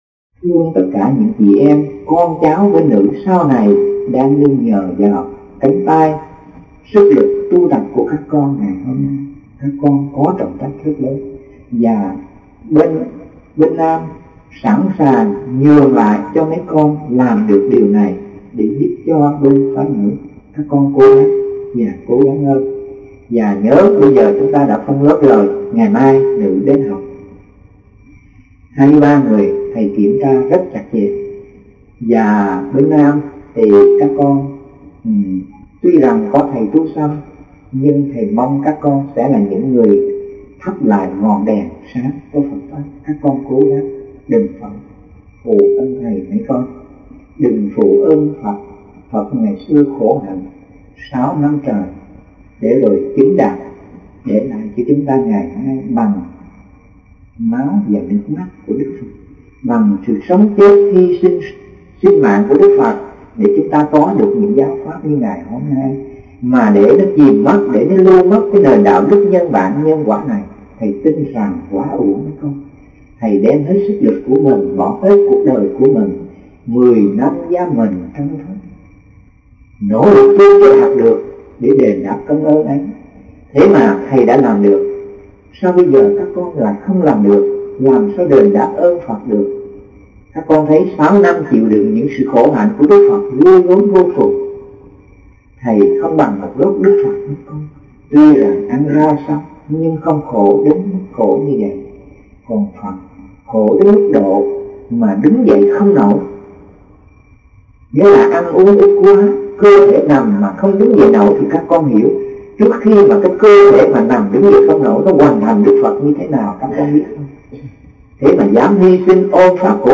Pháp Âm - 2005 - Lớp Chánh Kiến